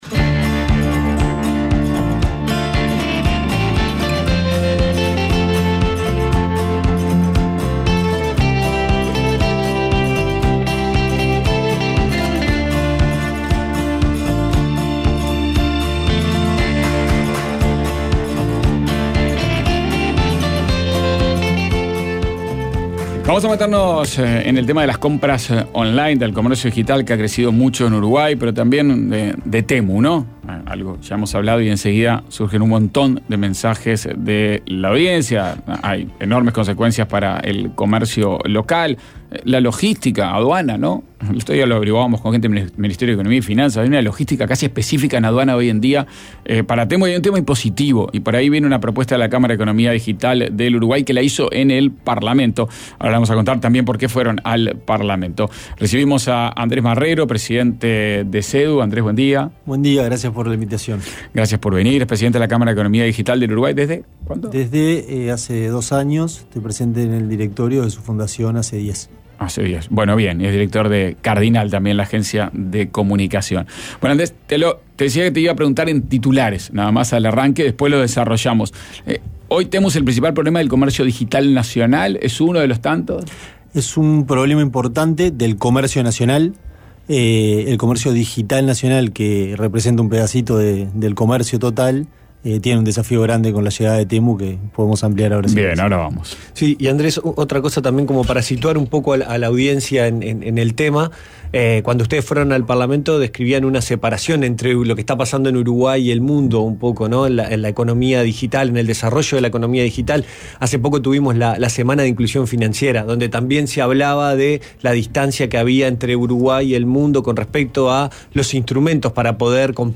Ronda con los coordinadores de dos "think medio tanks".